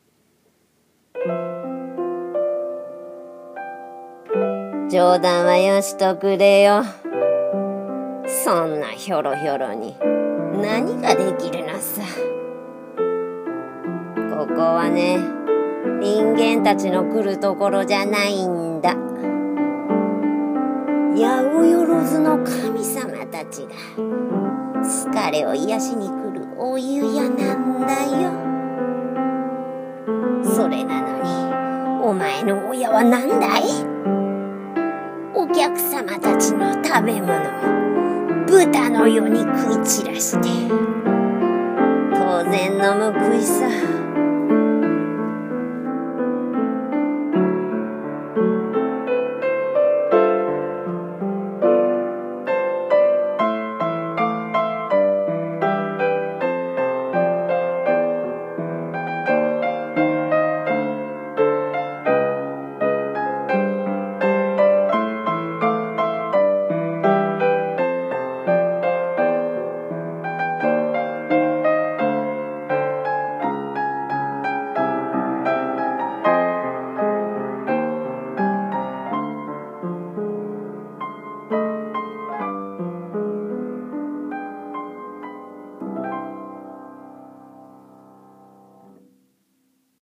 ものまね